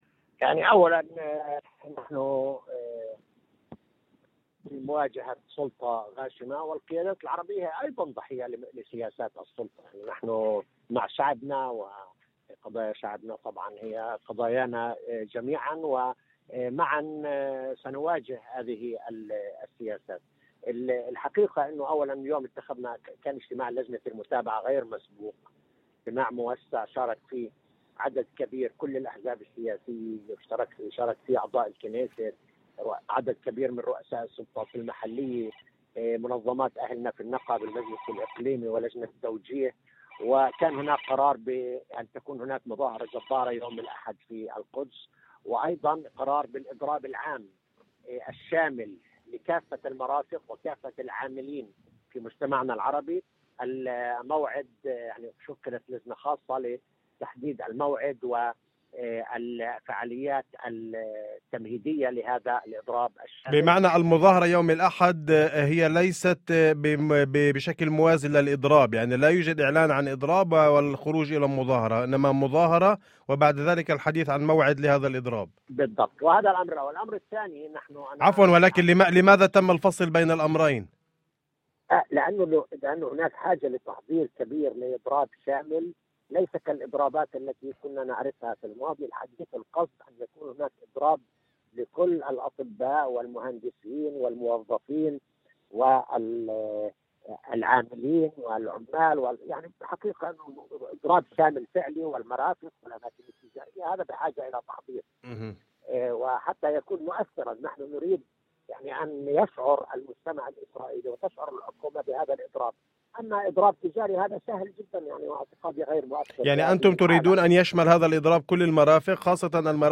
وقال زحالقة، في مداخلة عبر إذاعة الشمس، إن لجنة المتابعة عقدت اجتماعًا طارئًا وغير مسبوق، بمشاركة واسعة من الأحزاب السياسية، وأعضاء الكنيست، ورؤساء سلطات محلية، ومنظمات أهلية، ولجان توجيه، جرى خلاله اتخاذ قرارات تهدف إلى مواجهة ما وصفه بـ"سياسات السلطة التي فشلت في حماية المجتمع العربي".